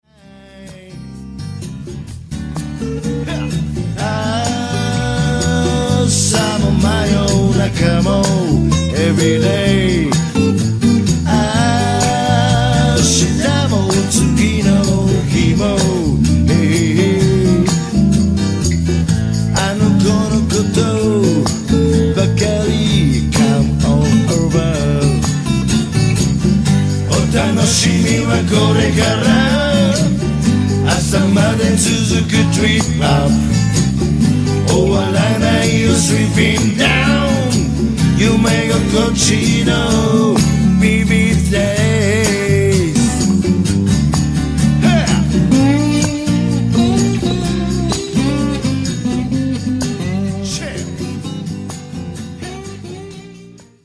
-Vo&Bass- -Vo&Guitars- -Vo&Ag&Bluesharp-